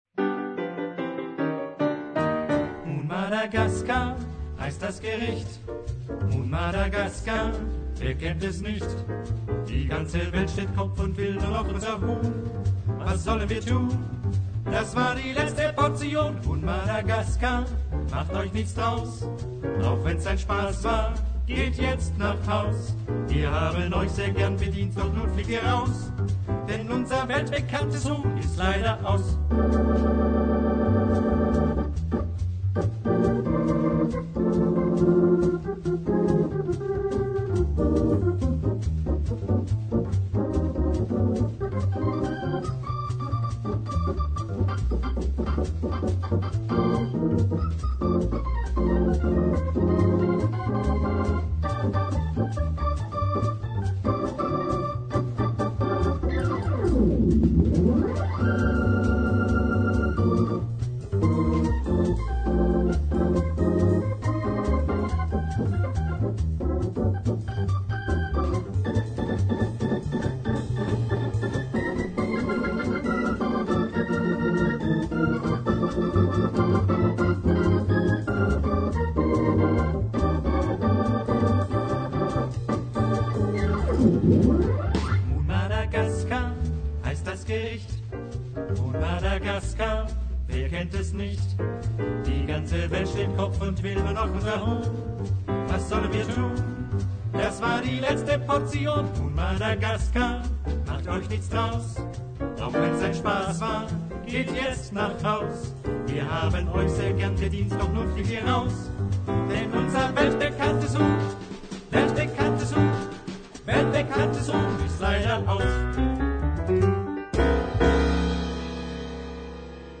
eine vierk�pfige Musikformation aus Stuttgart